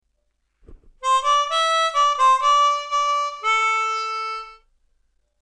C Major Chromatic (Hohner CX12)
Opening riff..